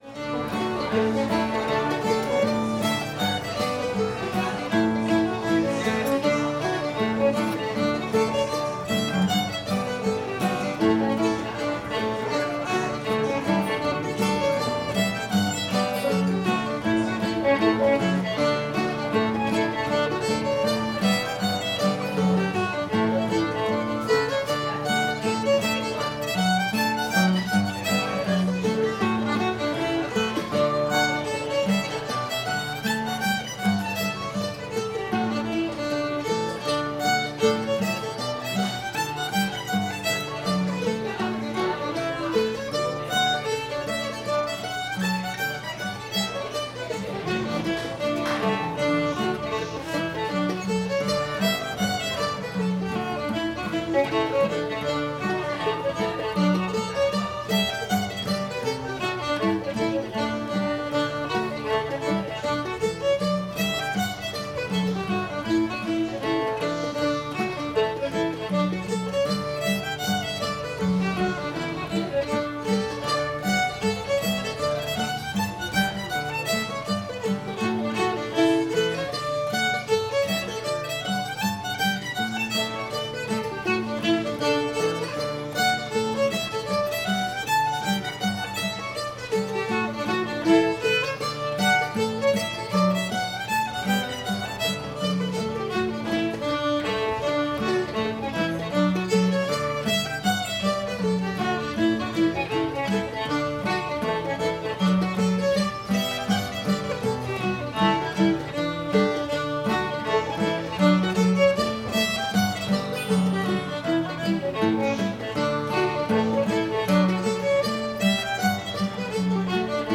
old virginia reel [D]